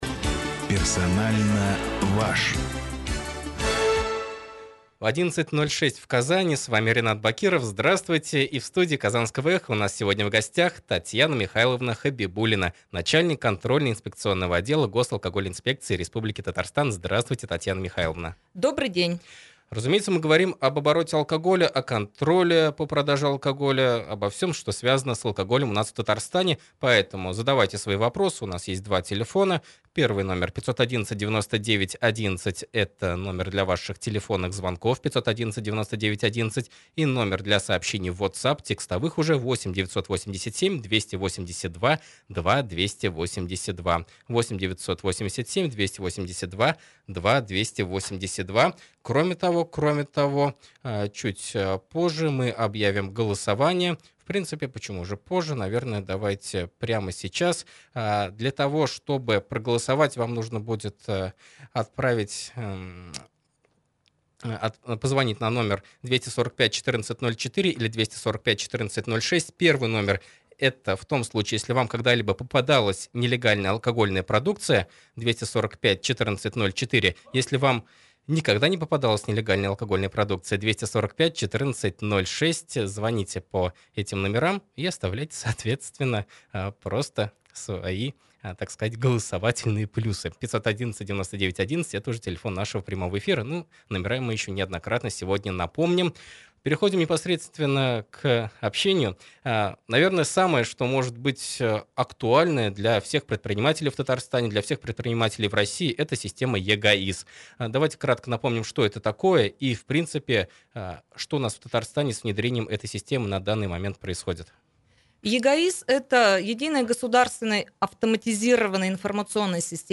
Прямой эфир на радио «Эхо Москвы» в Казани